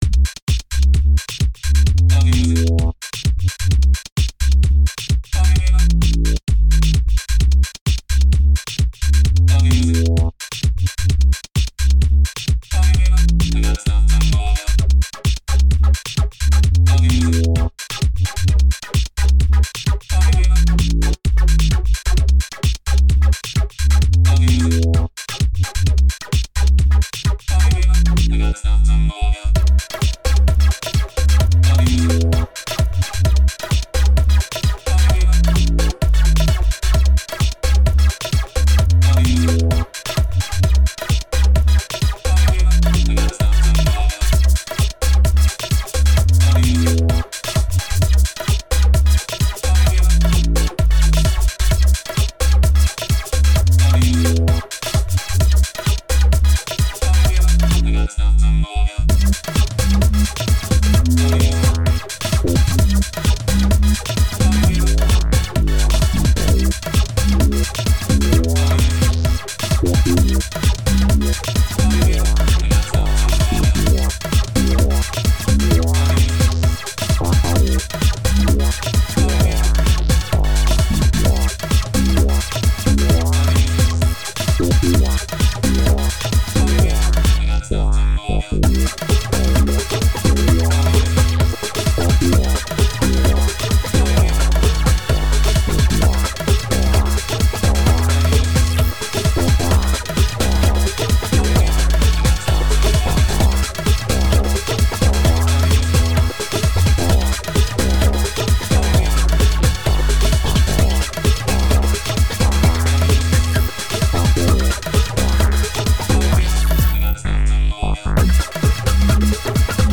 A bit full on. Funky